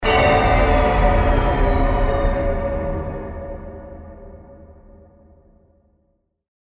tick.mp3